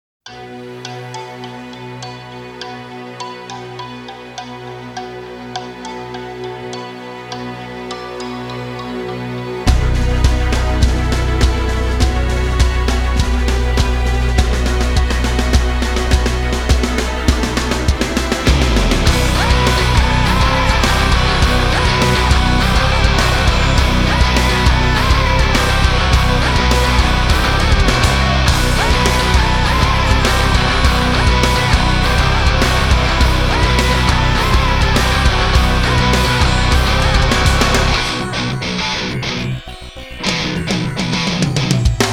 • Качество: 320, Stereo
громкие
женский вокал
Alternative Metal
Progressive Metal